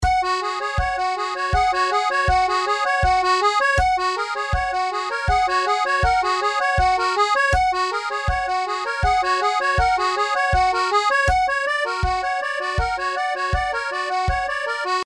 Akkordeonübungen zum Download
Wie komme ich im langsamen Swing von Subdominante F nach D (als Doppeldominante und Turnaraound zurück zum C) your_browser_is_not_able_to_play_this_audio oder von F Dur nach f-moll: